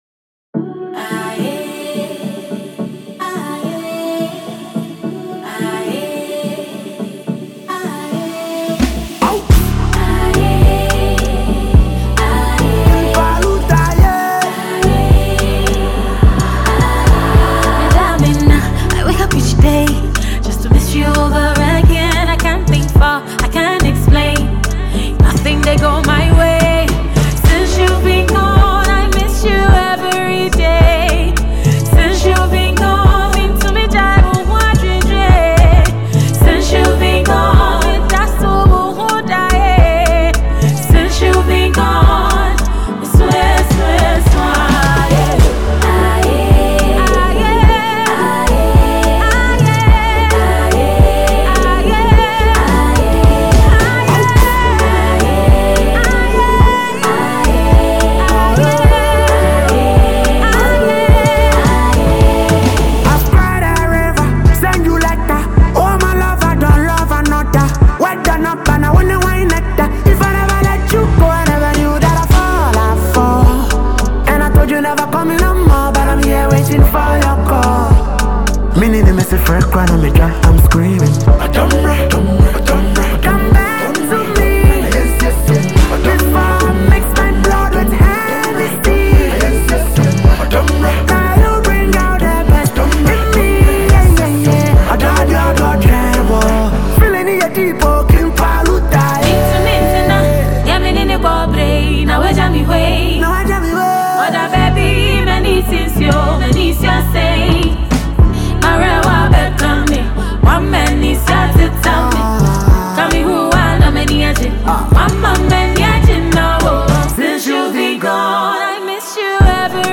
smooth, heartfelt vocals